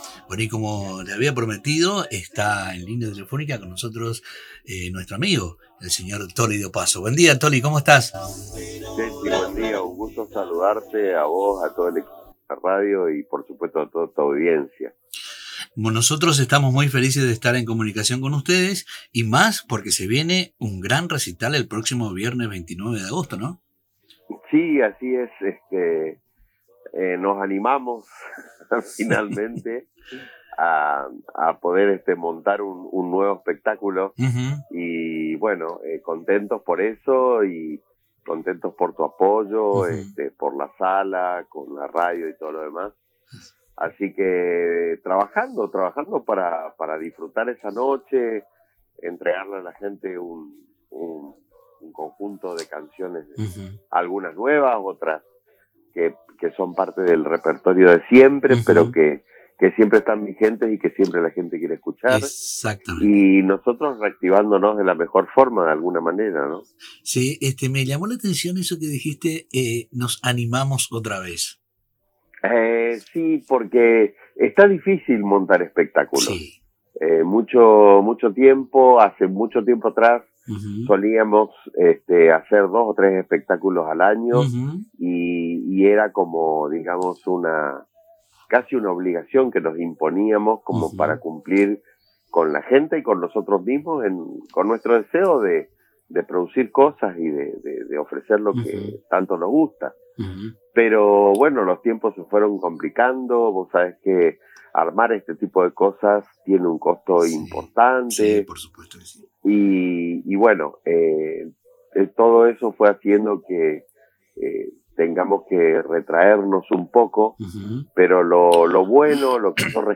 en diálogo con el programa Somos Música por Radio Tupambaé.